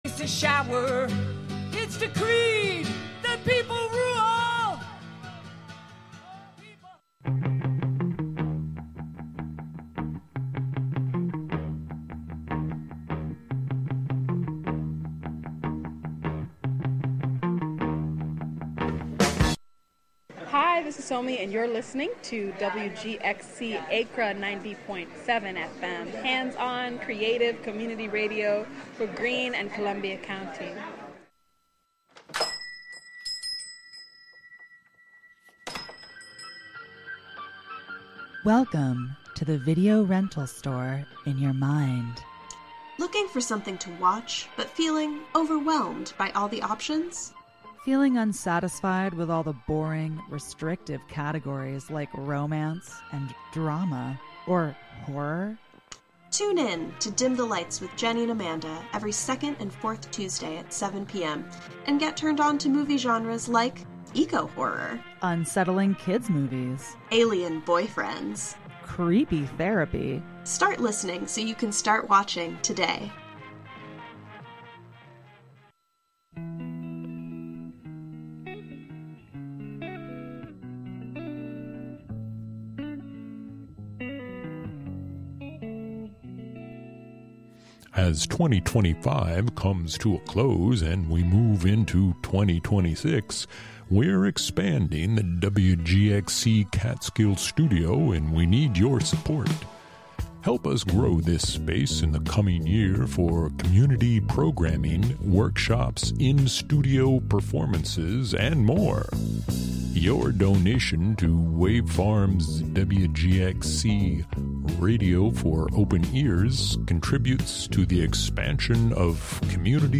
These features offer listeners real, raw, and authentic conversations.